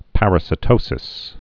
(părə-sĭ-tōsĭs, -sī-)